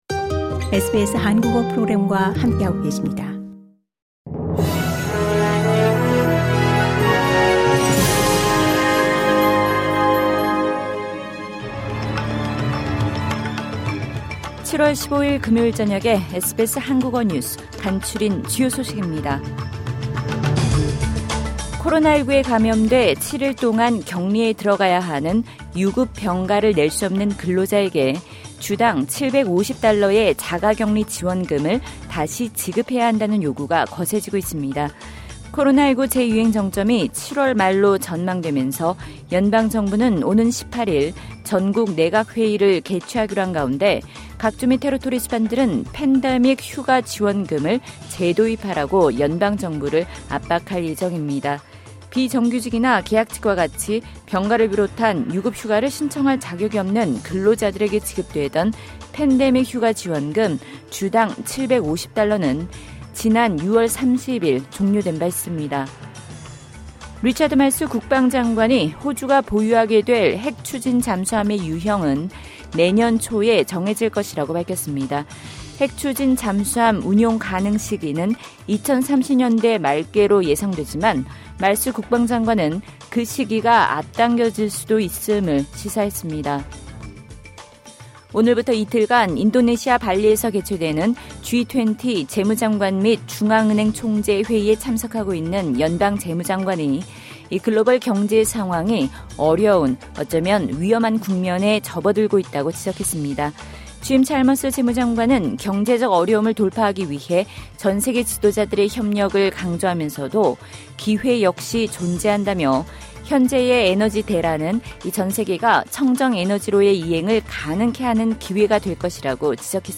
2022년 7월 15일 금요일 저녁 SBS 한국어 간추린 주요 뉴스입니다.